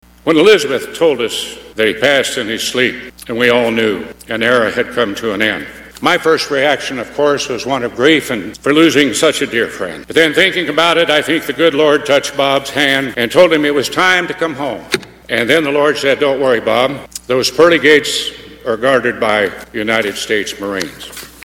Former Senator Pat Roberts was there to speak about Dole, not only as a politician, but as a friend.